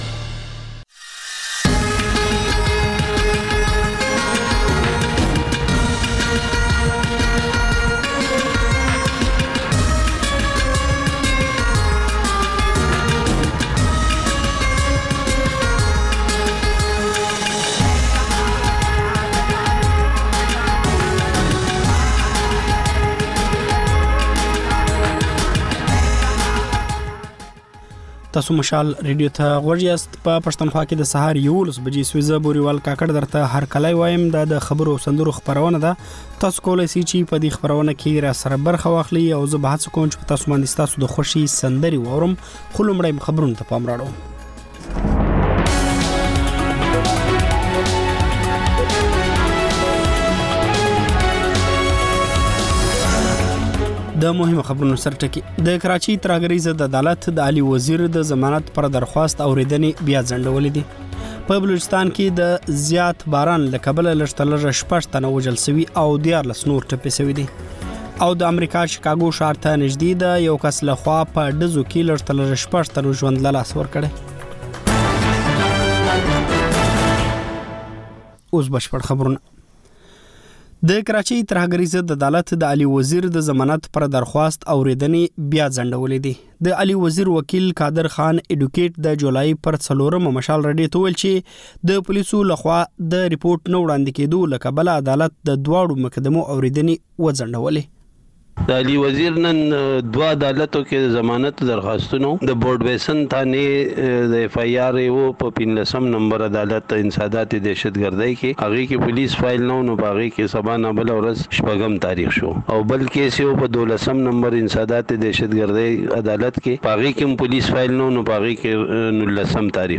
په دې خپرونه کې تر خبرونو وروسته له اورېدونکیو سره په ژوندۍ بڼه خبرې کېږي، د هغوی پیغامونه خپرېږي او د هغوی د سندرو فرمایشونه پوره کول کېږي.